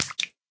flop1.ogg